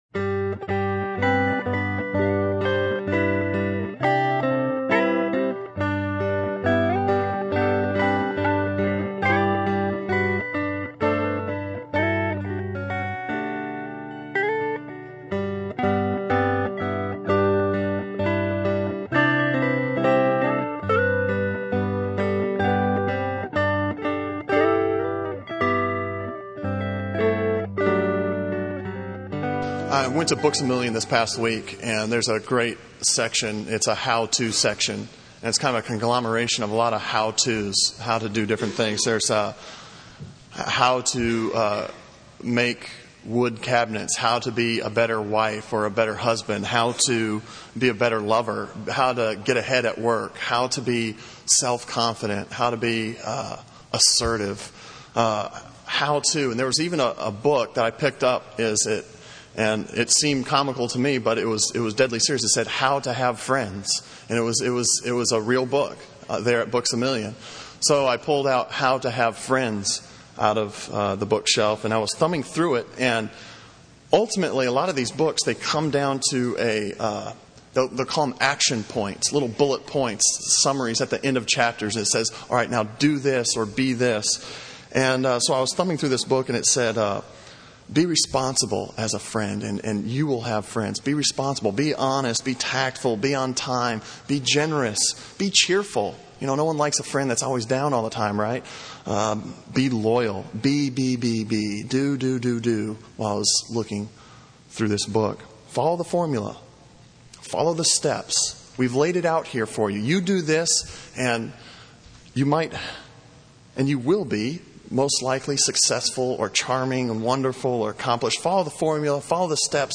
Sermon on Philippians 2:19-30 from October 15